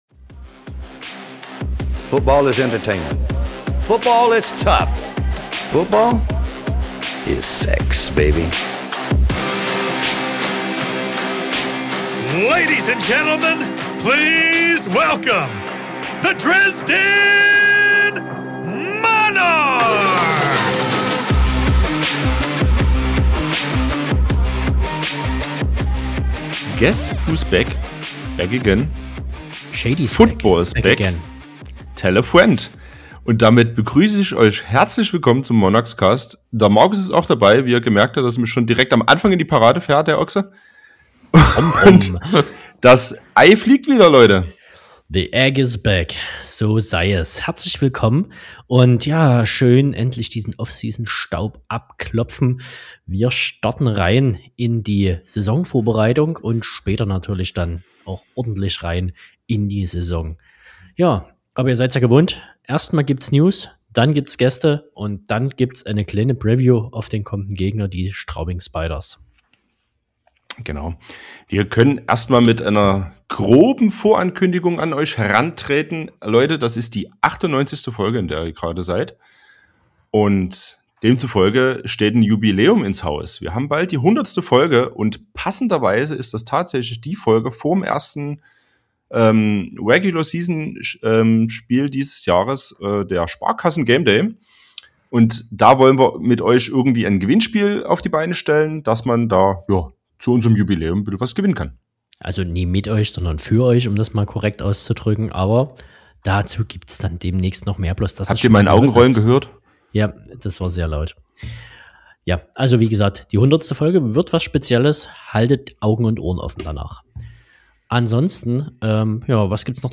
Das jährliche Testspiel gegen die Straubing Spiders steht ins Haus, der Kader füllt sich weiter und einige Spieler haben kleine Botschaften für euch dagelassen. Im heutigen Interview besprechen wir mit den beiden Jugend Flag Coaches